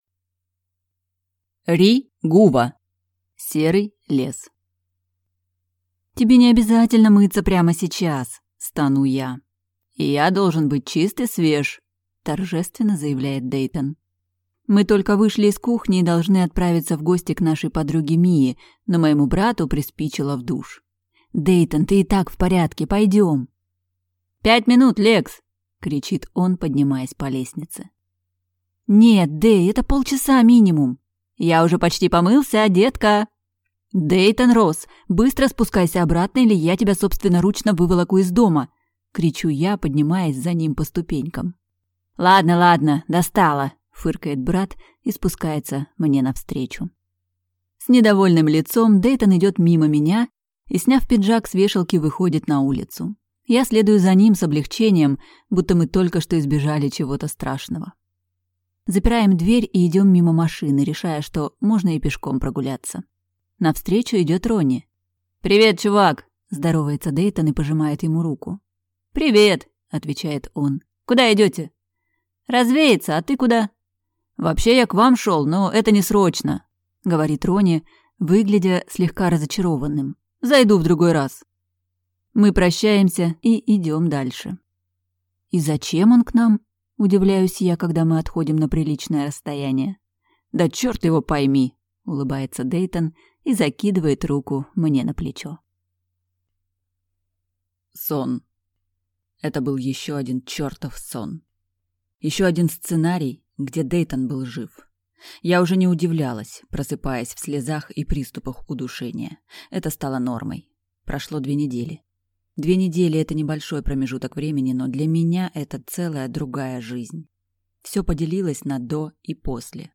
Аудиокнига Серый лес | Библиотека аудиокниг